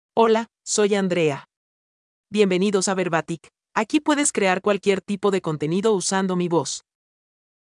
FemaleSpanish (Ecuador)
Andrea — Female Spanish AI voice
Voice sample
Female
Andrea delivers clear pronunciation with authentic Ecuador Spanish intonation, making your content sound professionally produced.